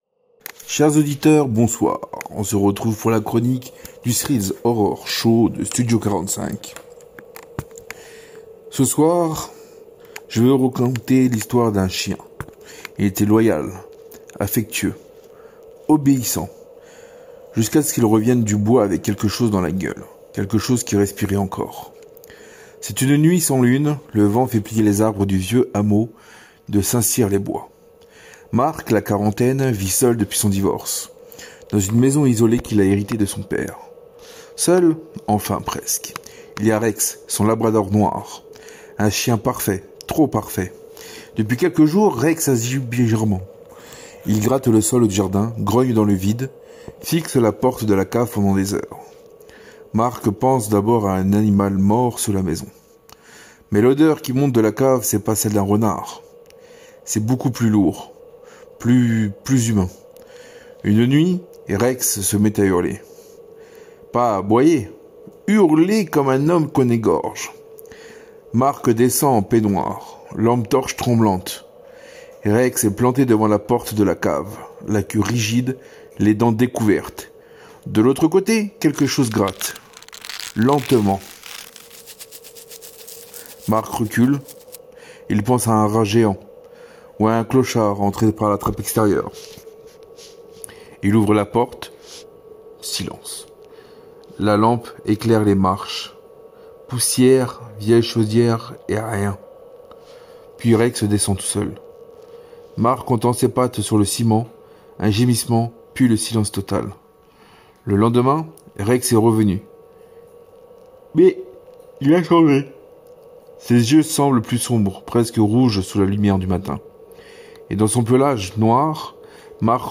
Bon Chien — Une fiction sonore d’horreur signée Studio 45
Cette fiction sonore d’horreur plonge l’auditeur dans une atmosphère suffocante, où chaque son devient une menace, chaque silence un avertissement.
Avec une réalisation immersive et un soin particulier apporté à l’ambiance sonore, Studio 45 signe ici un récit qui ne se contente pas de faire peur — il vous hante.